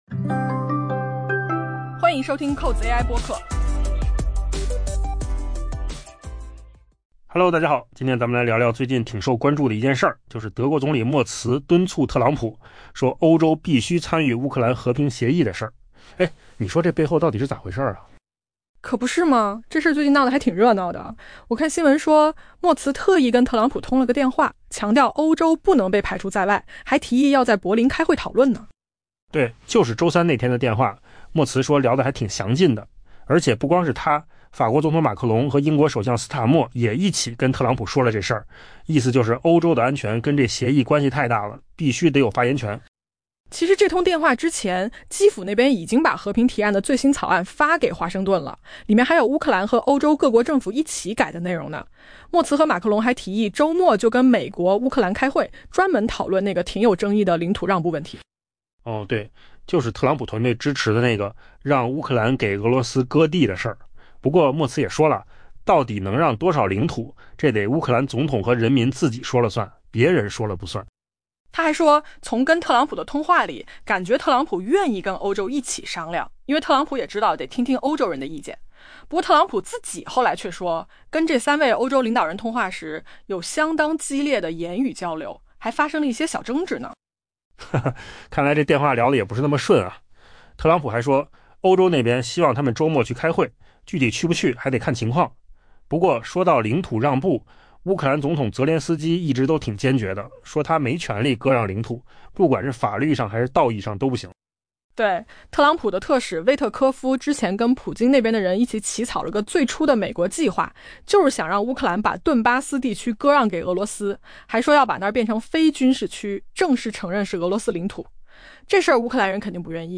AI 播客：换个方式听新闻 下载 mp3 音频由扣子空间生成 德国总理默茨敦促美国总统特朗普不要 「越过欧洲」 去达成乌克兰和平协议，并提议主办与华盛顿的会谈，讨论可能向俄罗斯做出的领土让步以确保停火。